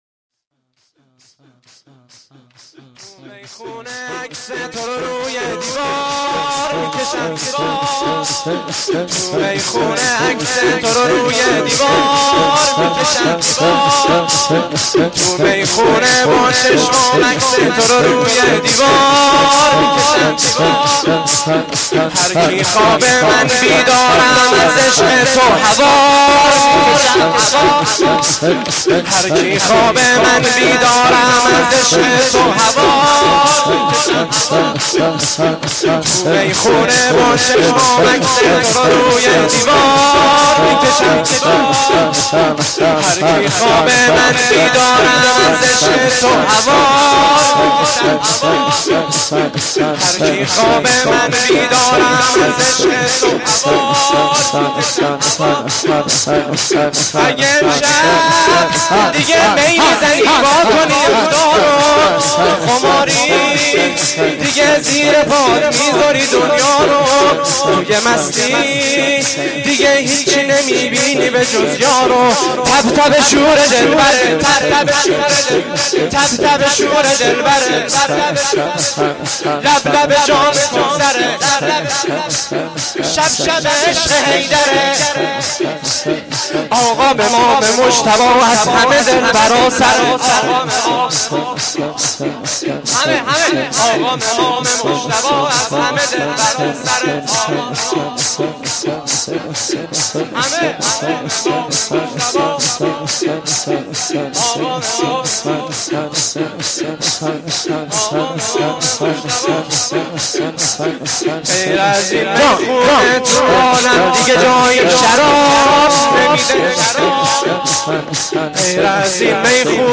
شور
shoor5-Rozatol-Abbas.Milad-Emam-Hasan.mp3